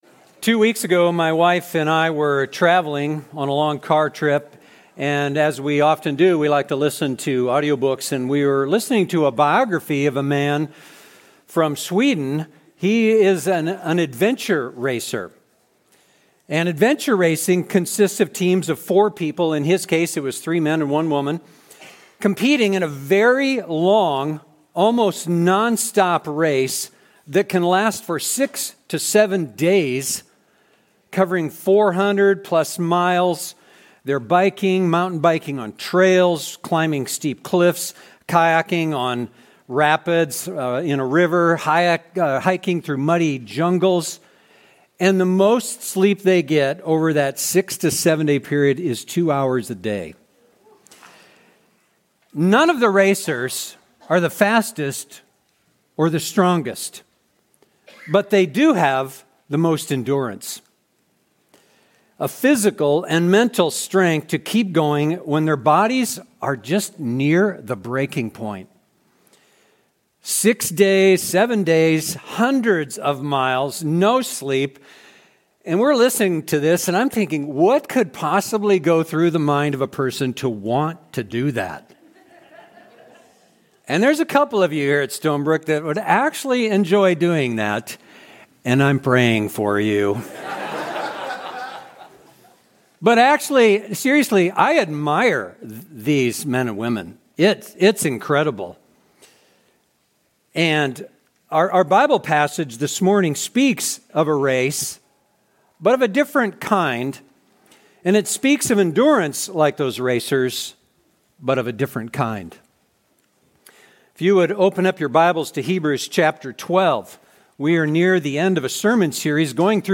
We are near the end of a sermon series going through this remarkable book.